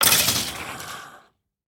Minecraft Version Minecraft Version snapshot Latest Release | Latest Snapshot snapshot / assets / minecraft / sounds / mob / stray / death1.ogg Compare With Compare With Latest Release | Latest Snapshot
death1.ogg